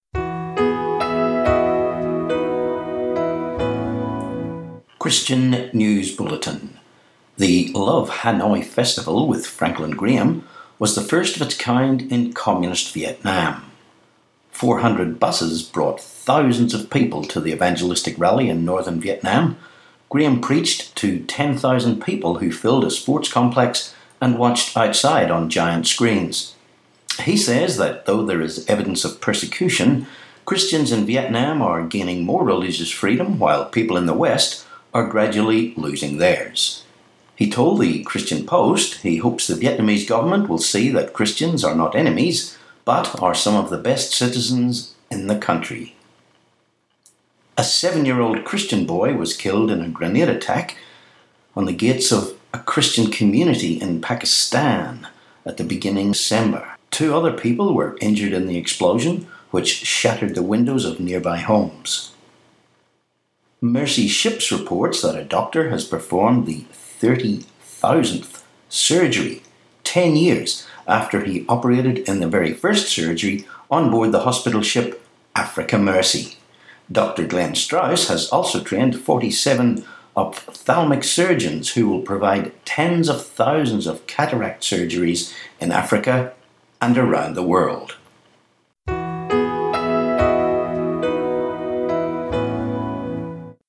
Christian News Bulletin, podcast